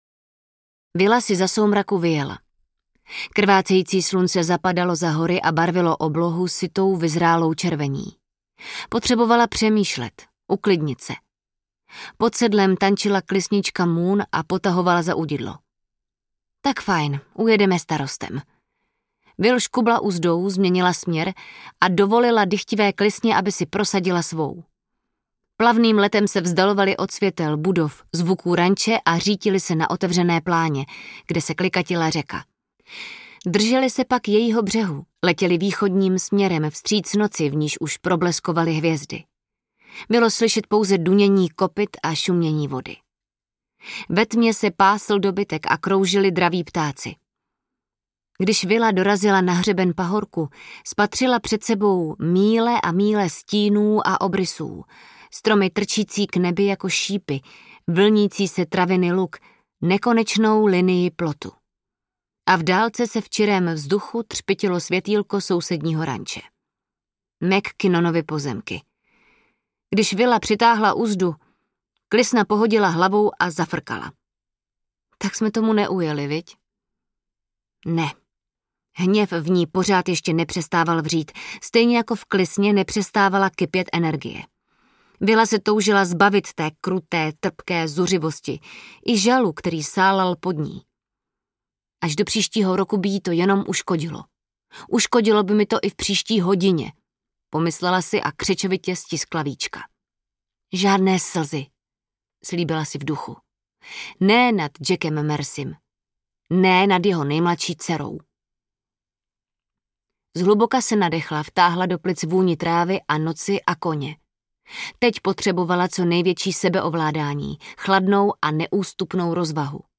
Nebe mé lásky audiokniha
Ukázka z knihy
nebe-me-lasky-audiokniha